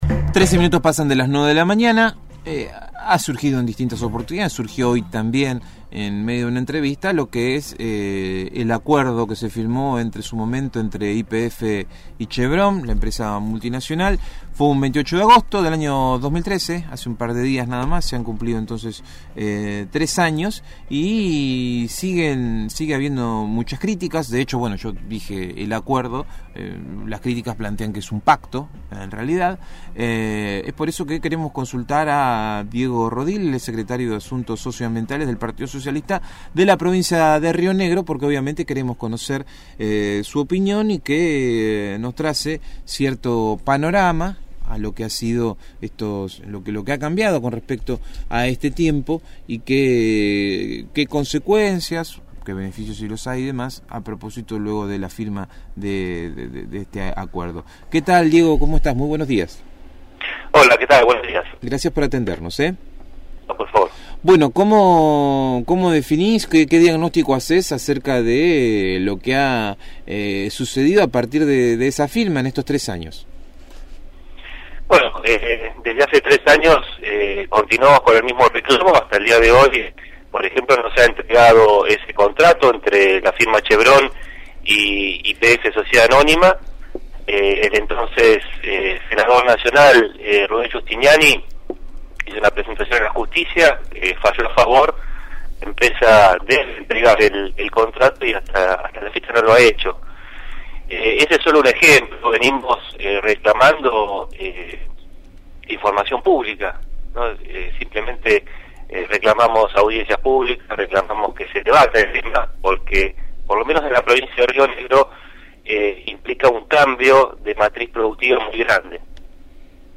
dialogó con el equipo de «El Hormiguero» sobre la modificación de la matriz productiva en la zona de Alto Valle.